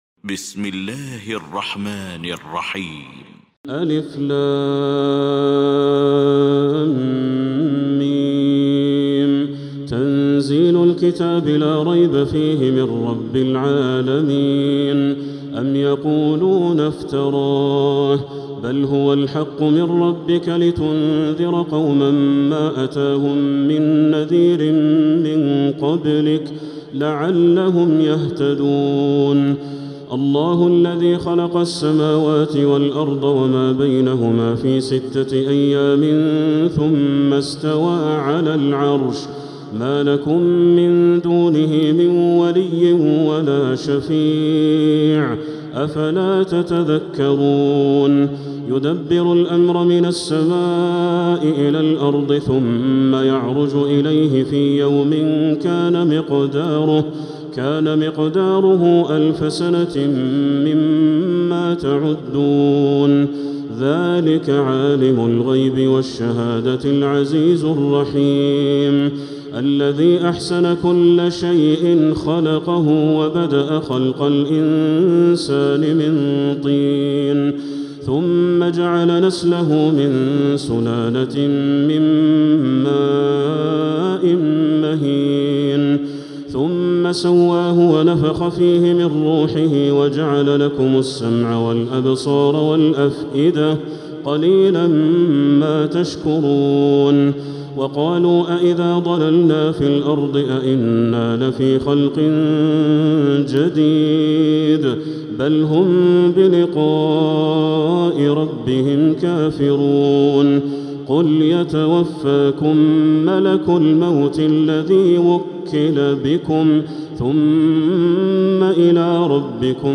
المكان: المسجد الحرام الشيخ: بدر التركي بدر التركي السجدة